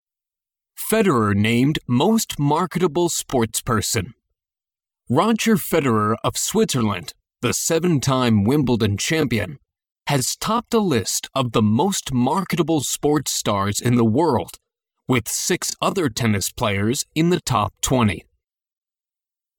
ネイティブ音声のリズムや抑揚に気を付け、完全にコピーするつもりで通訳トレーニングを反復してくださいね。